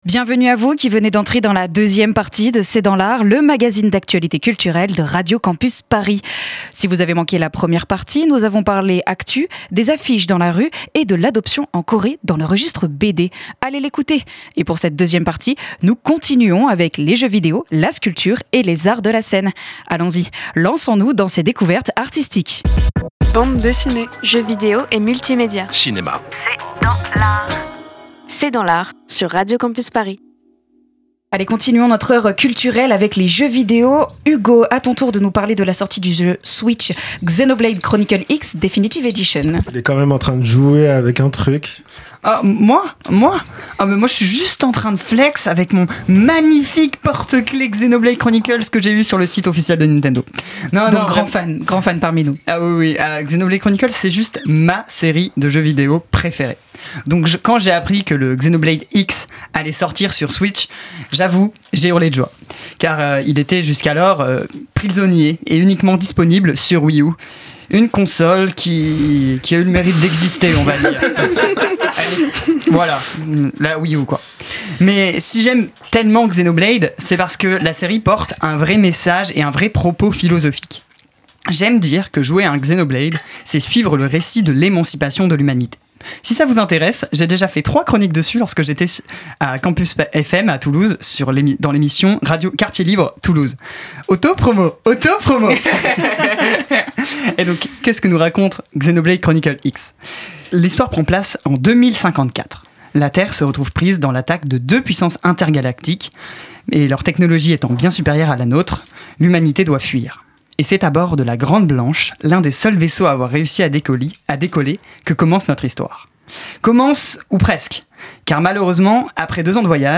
C’est dans l’art, c’est l’émission d'actualité culturelle de Radio Campus Paris.